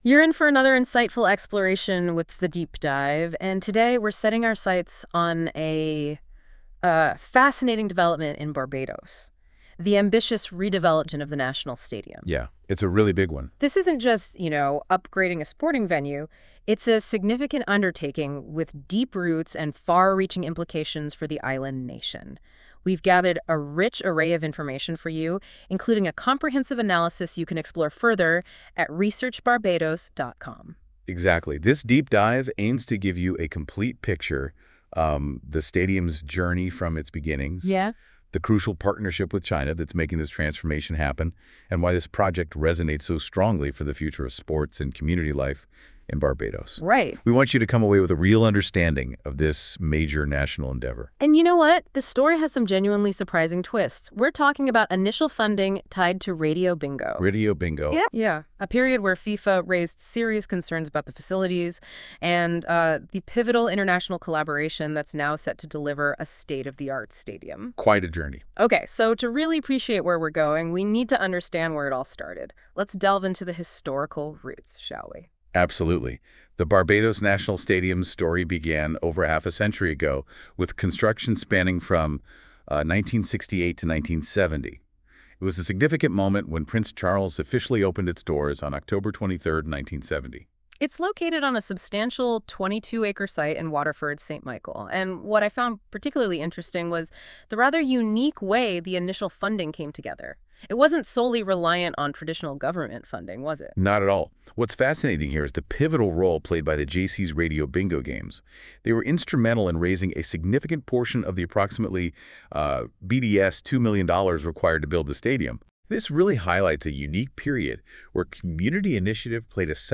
Expert commentary on Barbados' transformational stadium project, history and partnership